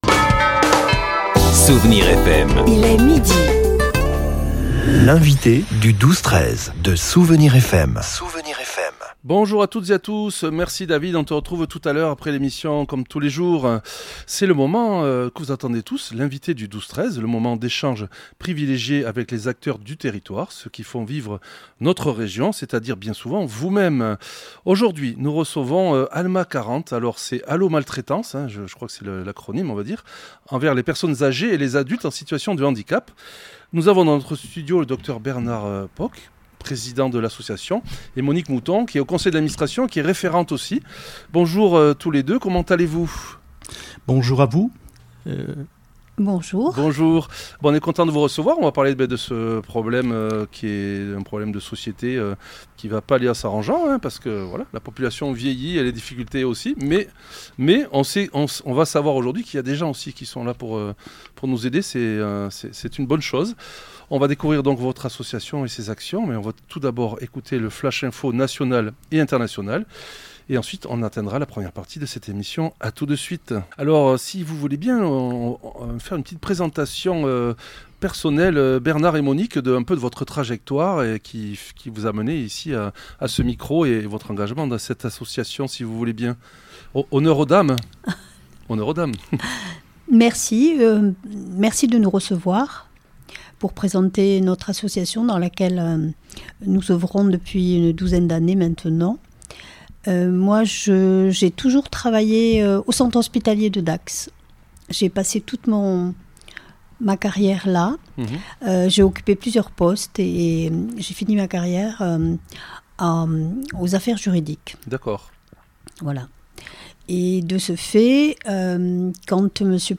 Nous recevions aujourd'hui ALMA40 : ALLÔ MALTRAITANCE ENVERS LES PERSONNES ÂGÉES ET LES ADULTES EN SITUATION DE HANDICAP.